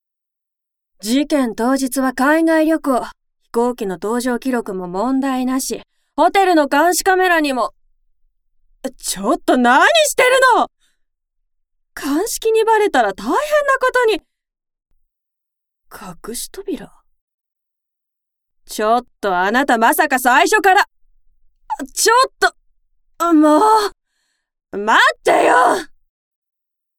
ボイスサンプル
台詞